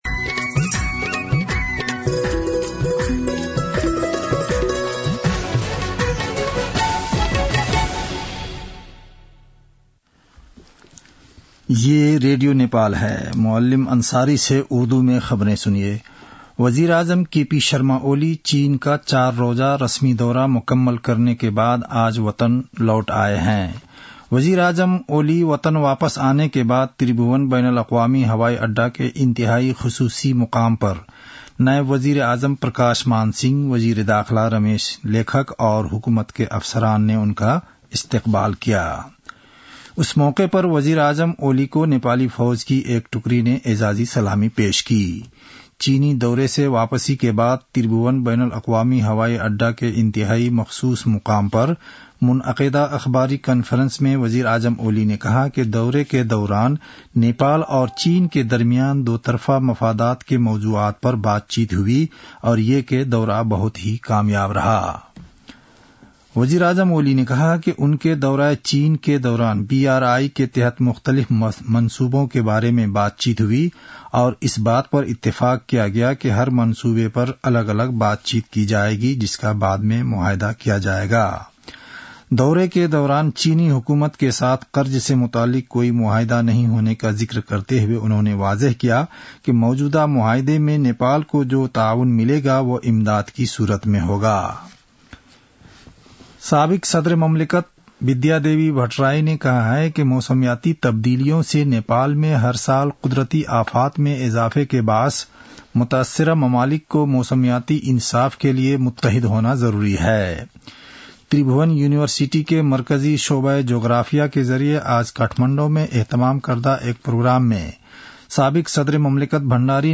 उर्दु भाषामा समाचार : २१ मंसिर , २०८१